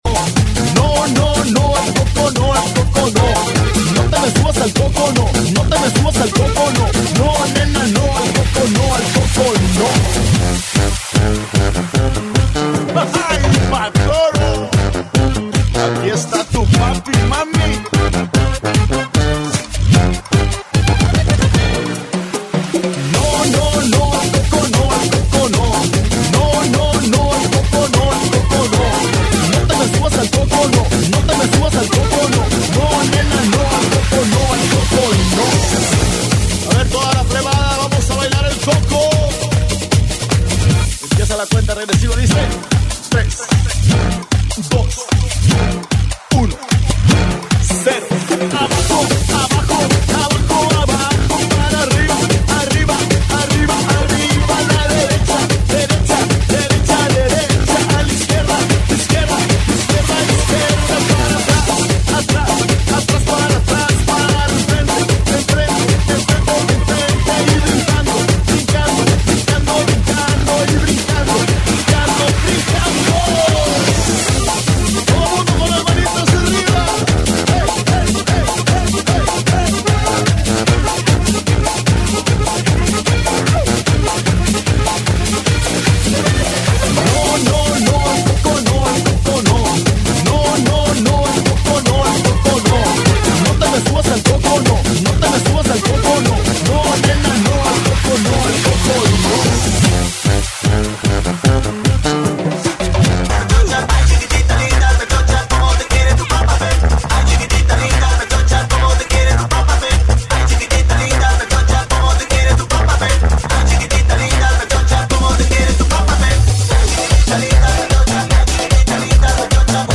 GENERO: BANDA
AEROBICS (STEP-HILOW)